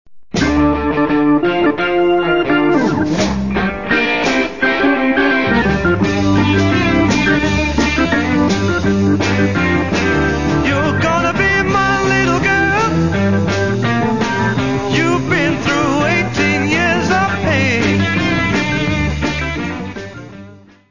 : stereo; 12 cm + folheto
Music Category/Genre:  Pop / Rock